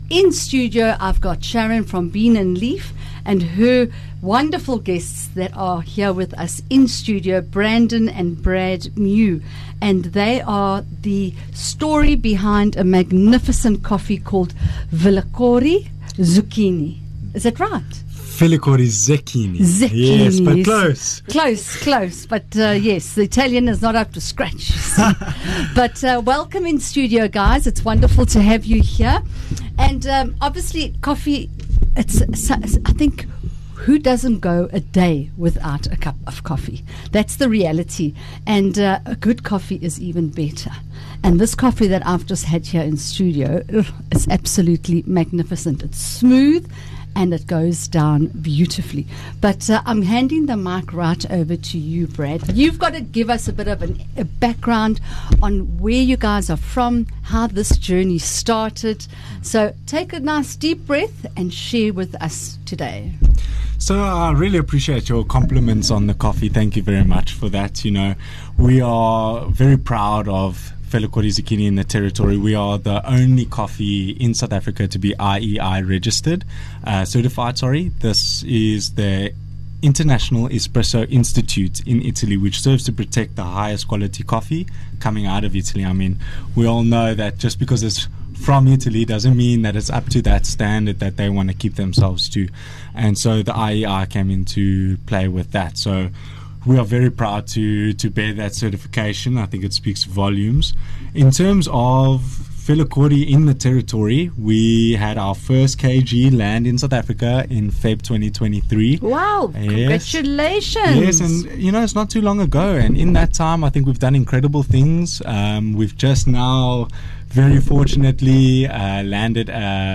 Radio Interviews 18 Jul Bean and Leaf